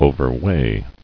[o·ver·weigh]